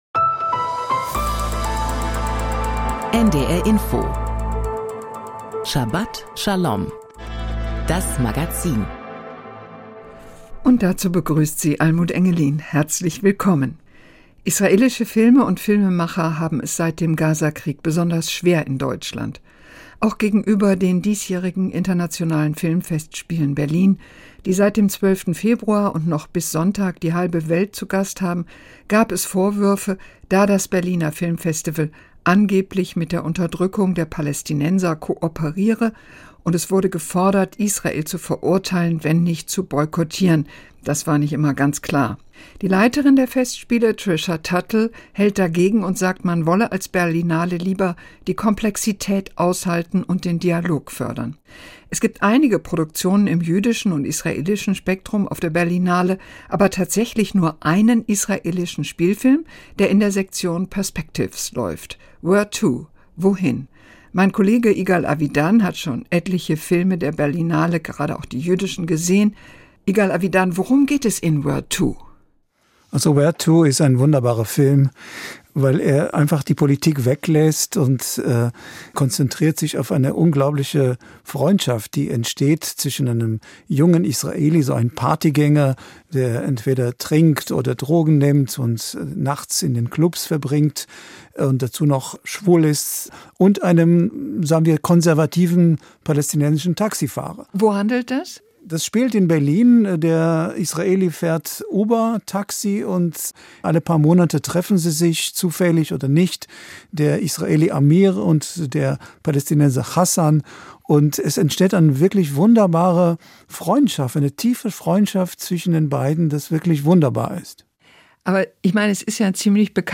Die Themen der Sendung: Jüdisches und Israelisches auf der Berlinale Interview
Thora-Auslegung